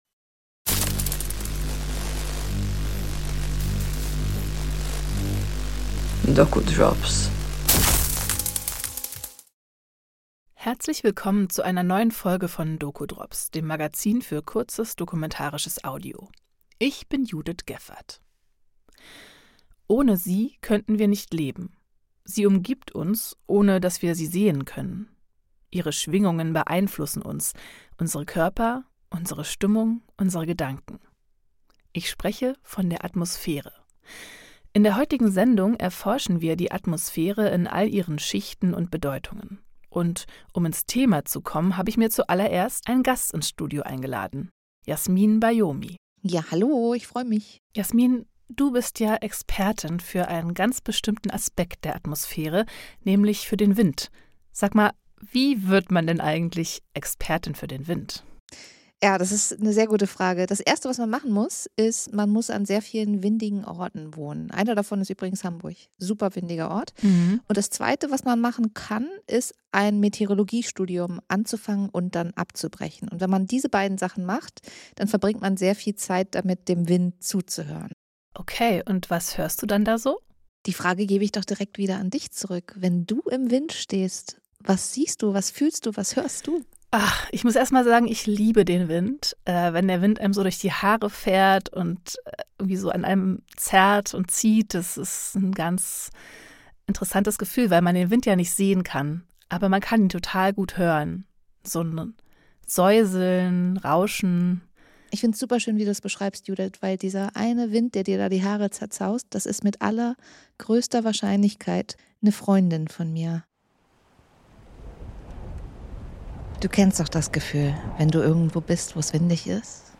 Feature Jeden Monat neue Kurzdokus doku drops 9: Atmosphäre 27:32 Minuten Monatlich neu: die doku drops.